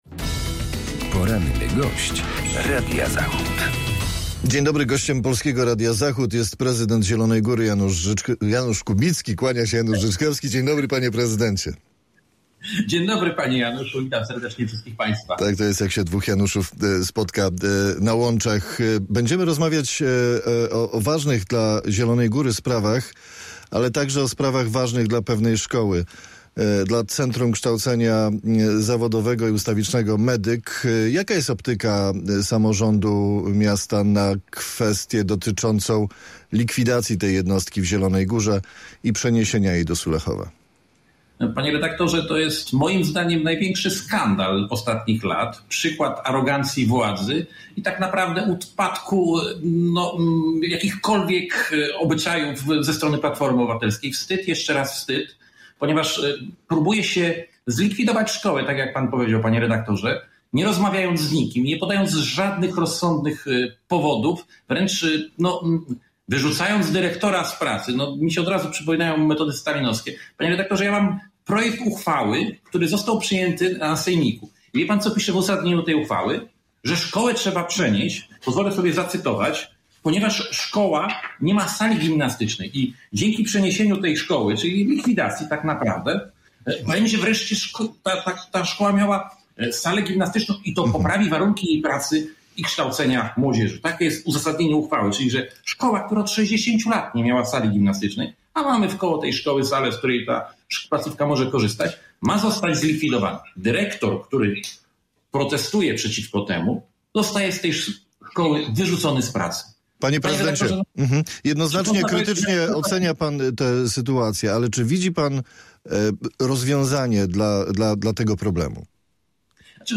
Z prezydentem Zielonej Góry rozmawia